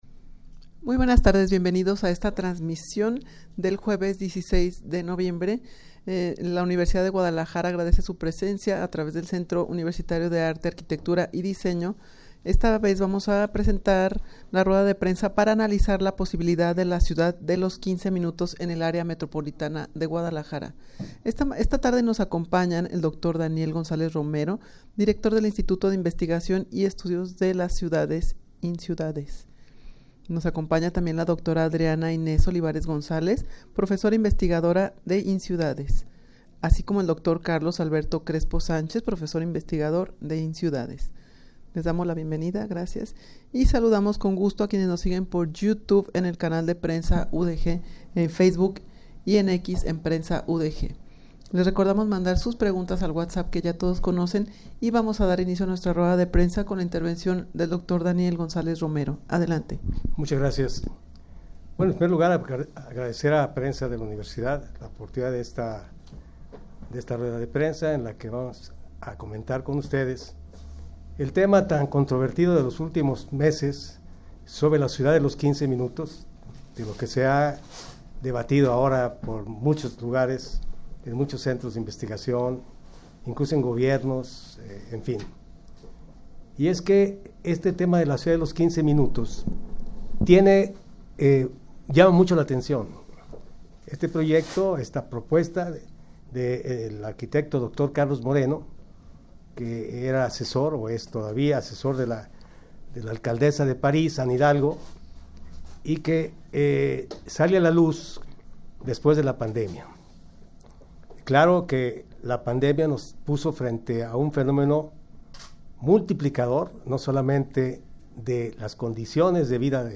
Audio de la Rueda de Prensa
rueda-de-prensa-para-analizar-la-posibilidad-de-la-ciudad-de-los-15-minutos-en-el-amg.mp3